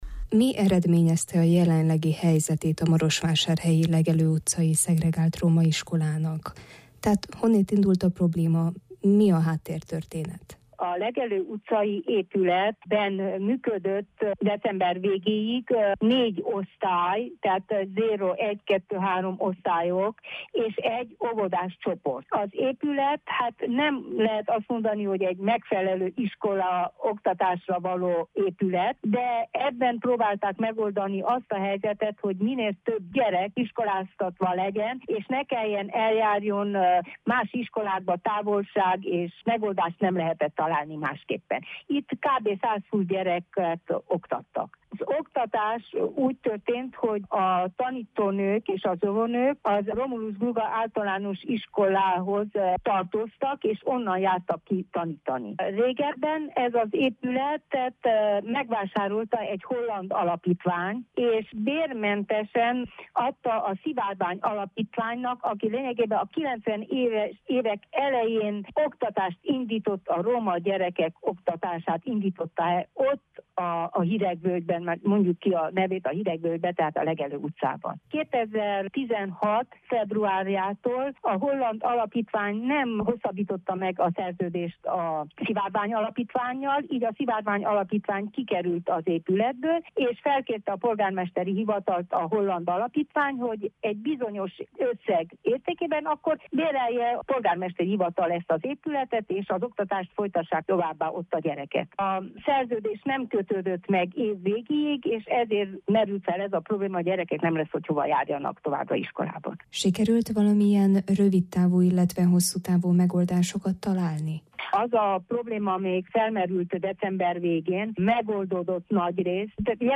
Furó Judit, a marosvásárhelyi önkormányzat RMDSZ tanácsosa megkérdezésünkre elmondta, hogy sikerült megoldásokat találni, mivel a polgármesteri hivatalnak az iskolákkal foglalkozó osztálya felvette a kapcsolatot a holland alapítvánnyal és követeléseik a január végi tanácsülésen tárgyalásra kerülnek.